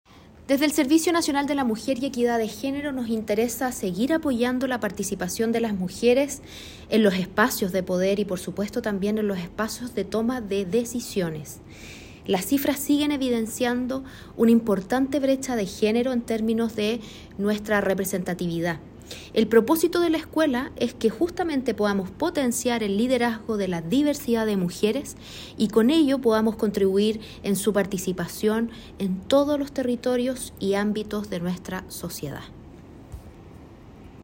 Priscilla-Carrasco-directora-nacional-SernamEG.mp3